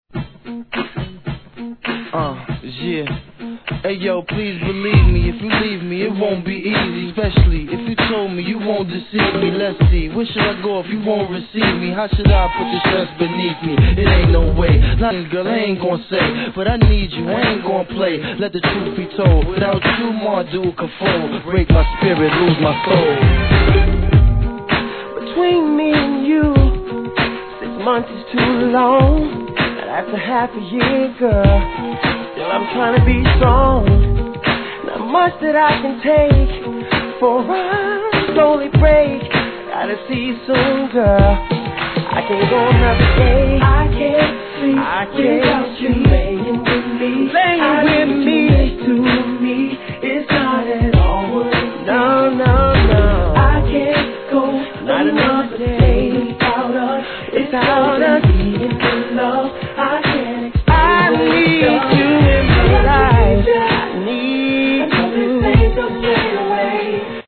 HIP HOP/R&B
SEXYに歌い上げる男性ヴォーカル物。